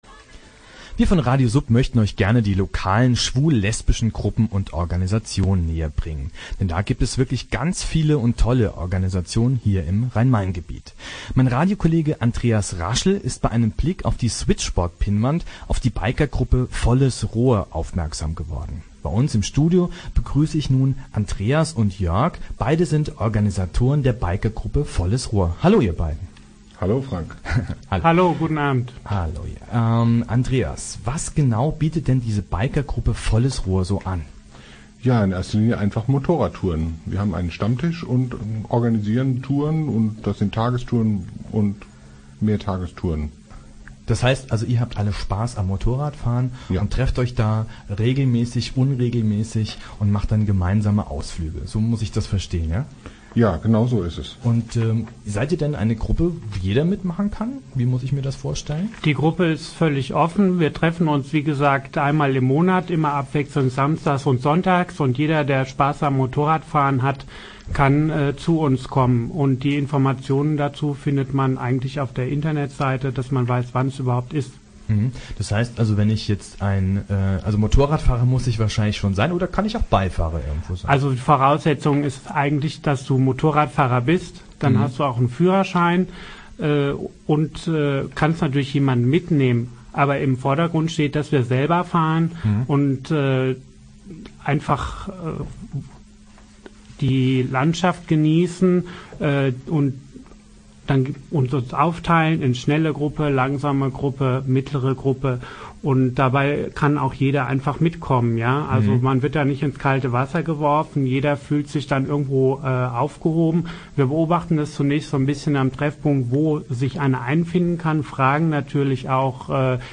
Er lud uns zu einem Live Interview im Studio von RadioSUB! ein. RadioSUB macht eine Sendereihe über die Gruppen, die sich regelmäßig im Switchboard treffen.